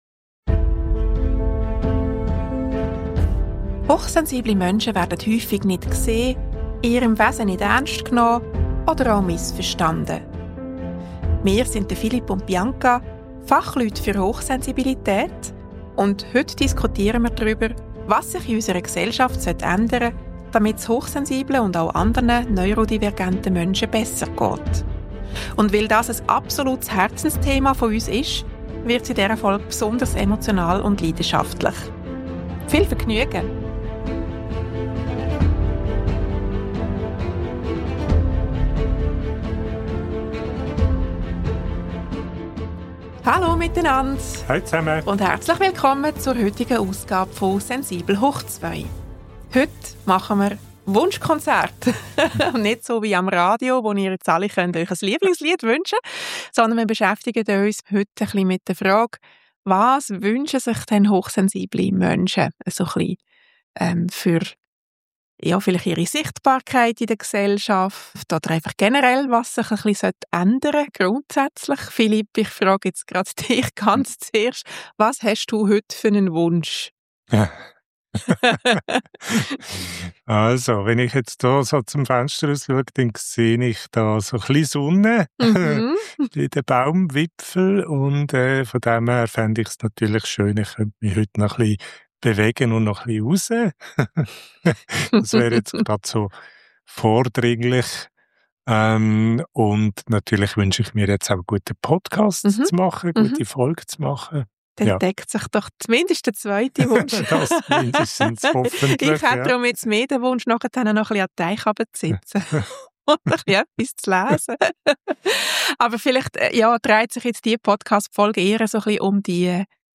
Weil Aufklärung, Bildung und Sensibilisierung zum Thema «Hochsensibilität» eins unserer absoluten Herzensthemen ist, diskutieren wir in dieser Folge leidenschaftlich über Wünsche, die wir als hochsensible Menschen an die Gesellschaft hätten. Und wir fragen uns, was es für unsere Welt bedeuten würde, wenn im Weissen Haus ein hochsensibler Mensch sitzen würde...